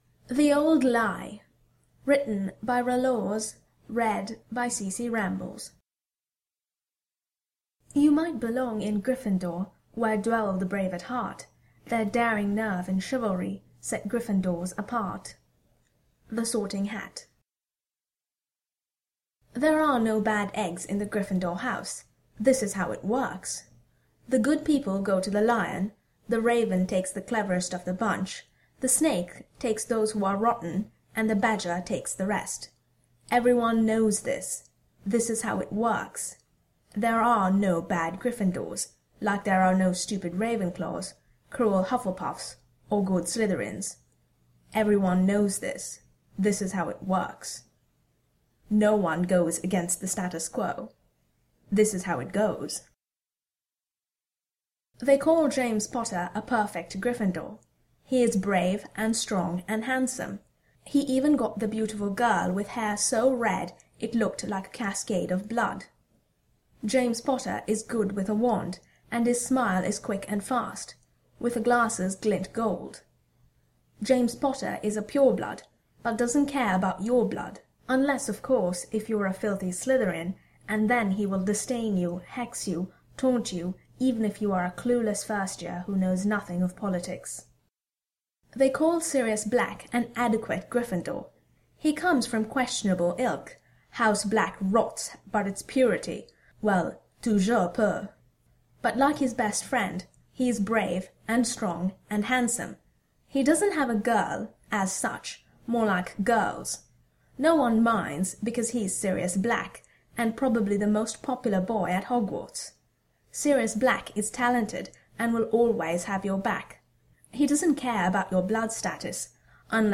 Podfic: the old lie